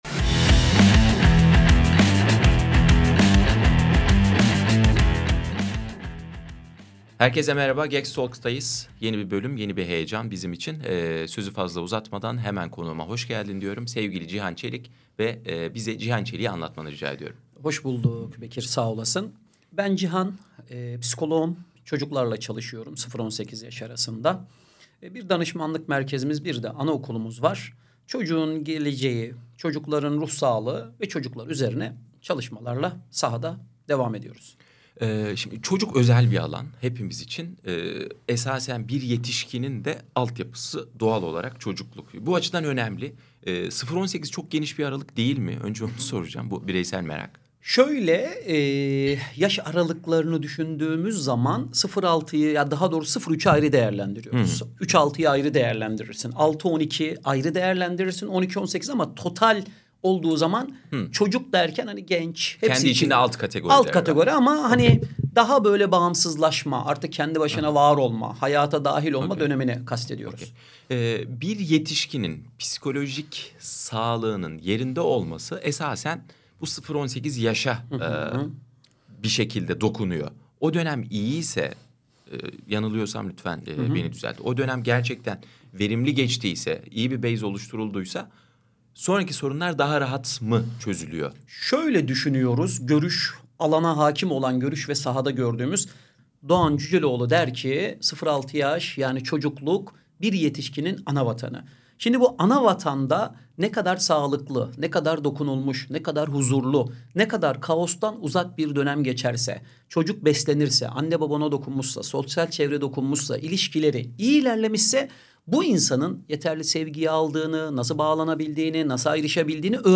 çocuk gelişimi ve oyunlaştırma üzerine keyifli bir sohbet gerçekleştirdik. Bu bölümde, ebeveynlerin ve eğitimcilerin, çocukların gelişim süreçlerine nasıl katkıda bulunabileceklerini, oyunlaştırma yöntemlerini eğitimle nasıl birleştirebileceklerini konuştuk.